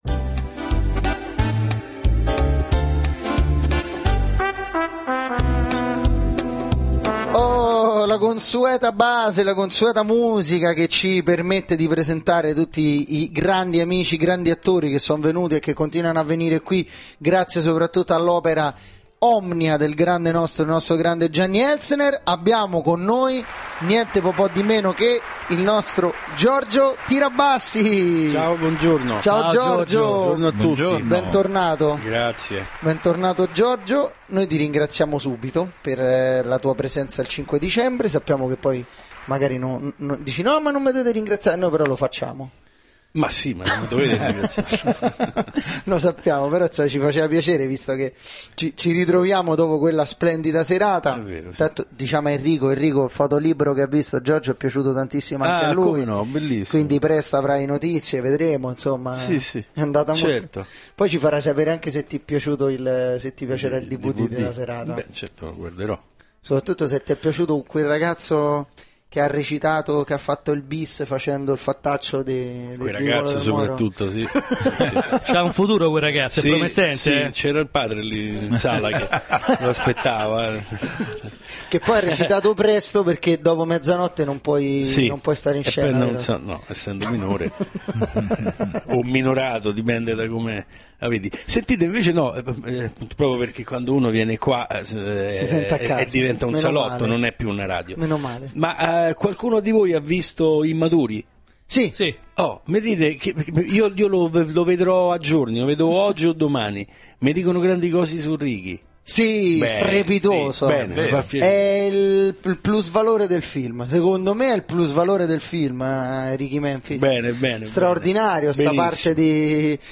Intervento in studio Giorgio Tirabassi del 24/03/2011 - prima parte